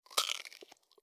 mixkit-meat-hit.ogg